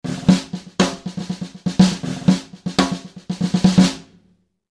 Snare drum samples.
kentchampagneloose.mp3